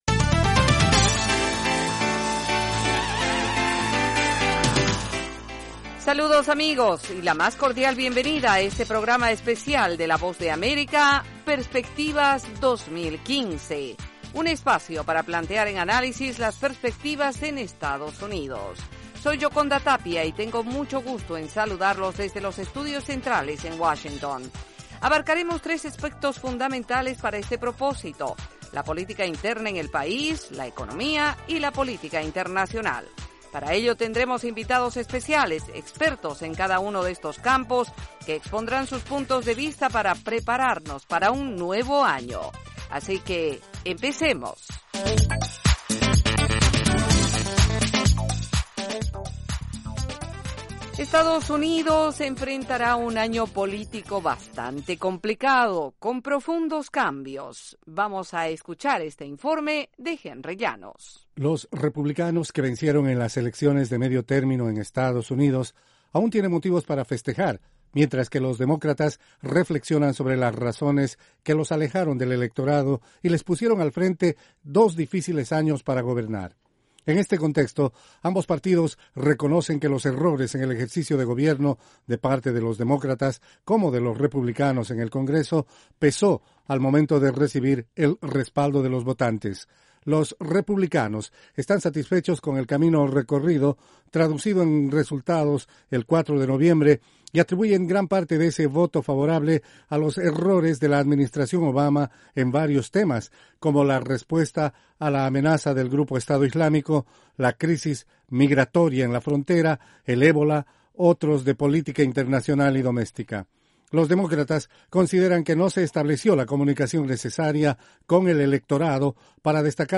[Duración 30 minutos con cortes para comerciales].